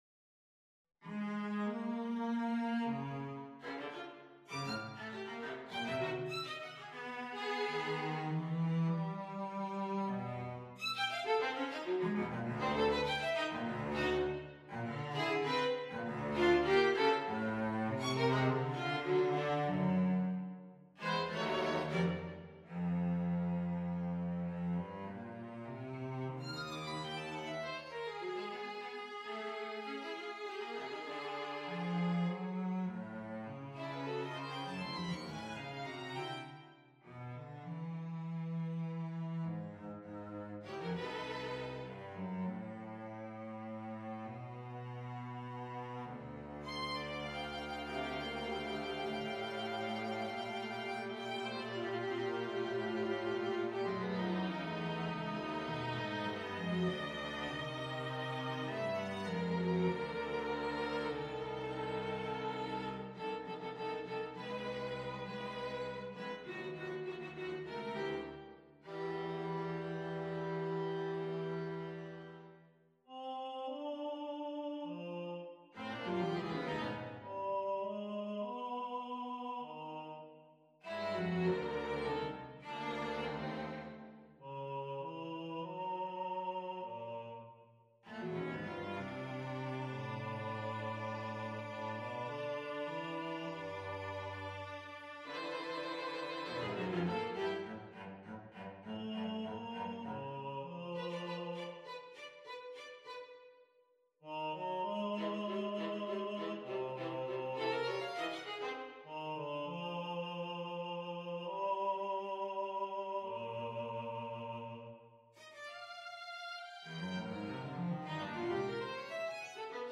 on a purpose-selected tone row
As always, every potential tonal center of the tow itself is exercized in this brief little blurt: Gb-Bb-A-C-D-F-G-Ab-B-Eb-Db-E. The vocal line begins at C and concludes in Db, while the strings finish in E.